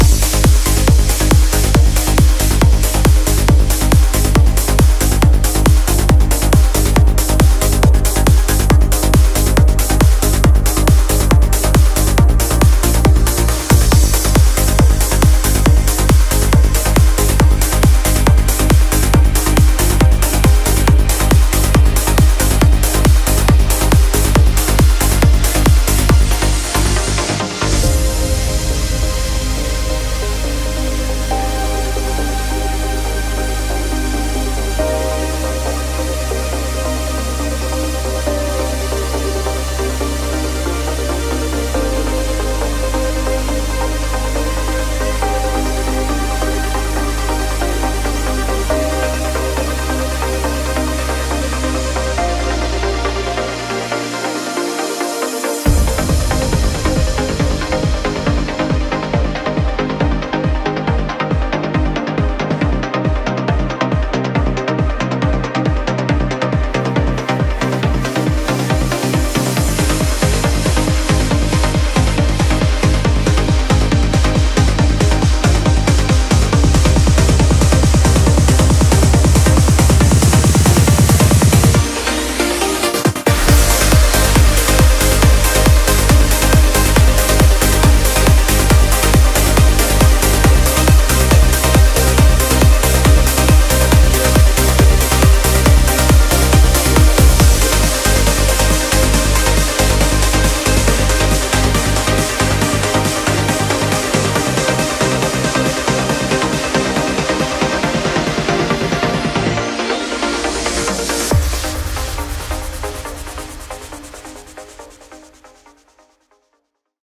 BPM69-138
Audio QualityPerfect (High Quality)
コメント[TRANCE